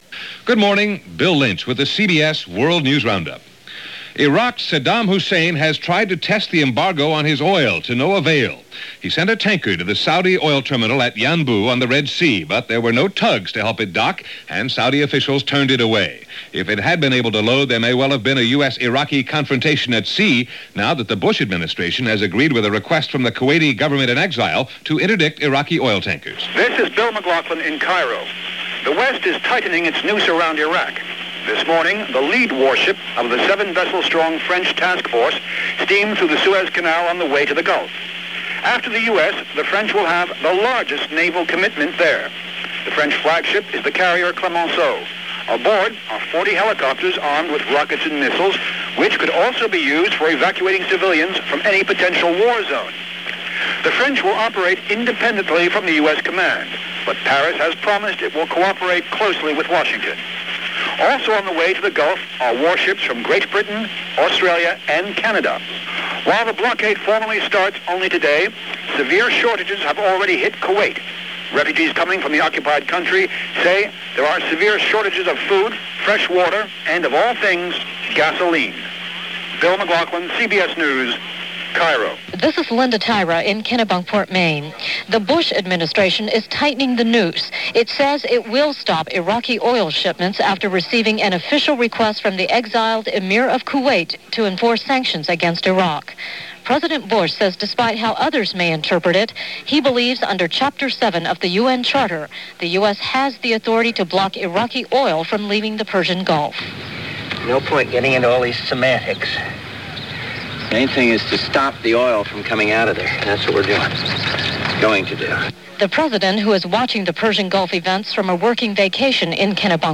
And there was a lot more going on – but the main news was the Gulf, and what would eventually become Desert Storm, as reported this August 13, 1990 by The CBS World News Roundup.